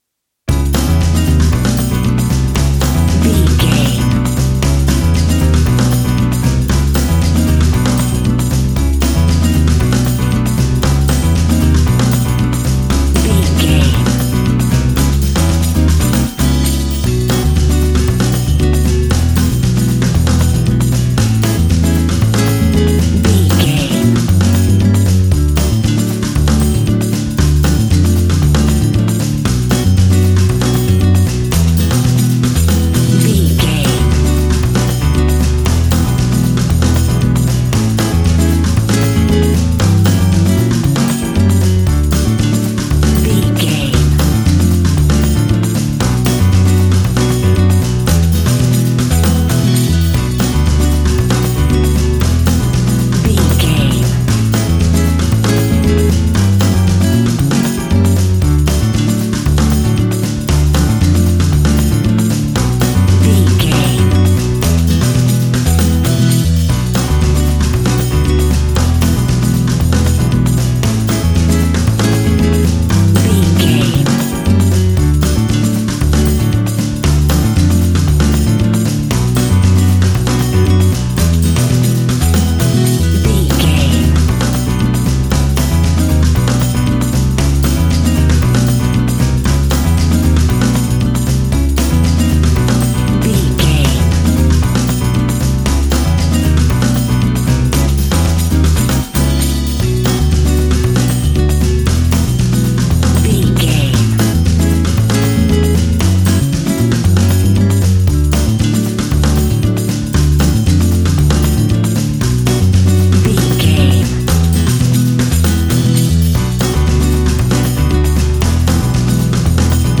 An exotic and colorful piece of Espanic and Latin music.
Ionian/Major
flamenco
maracas
percussion spanish guitar